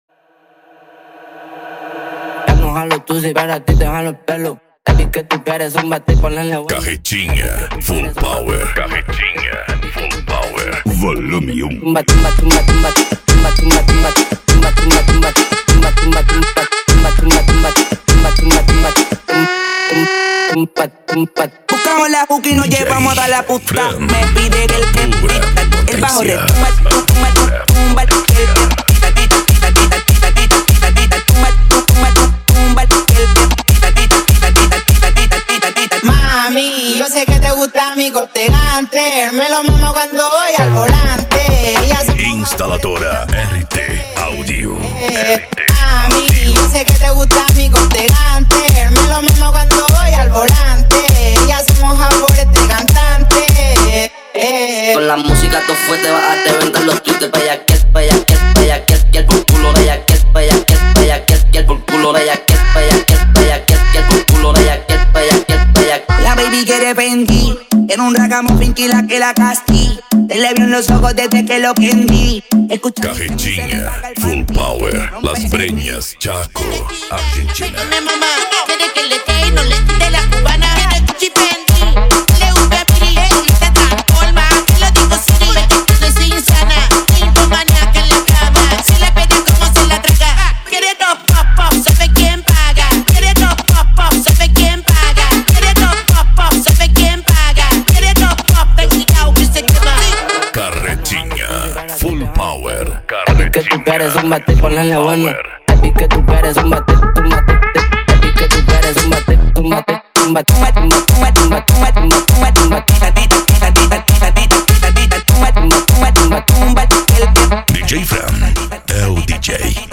PANCADÃO
Racha De Som
Remix